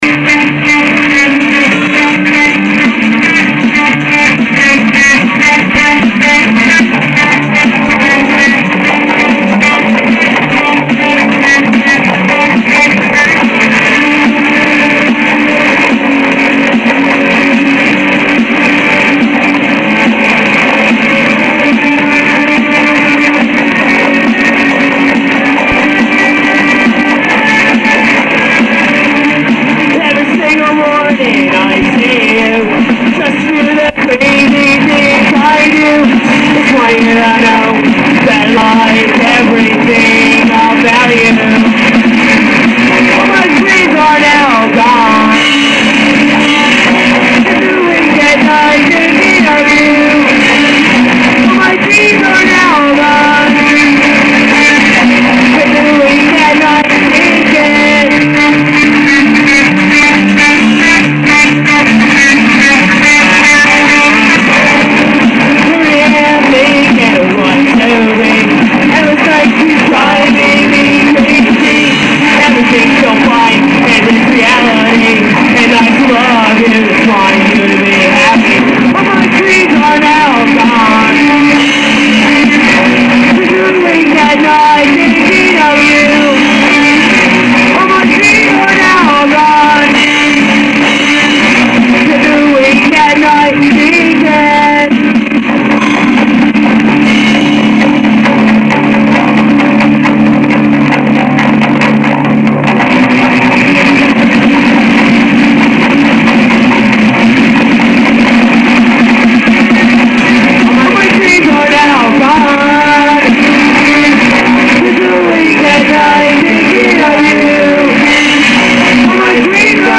tomorrow is band practice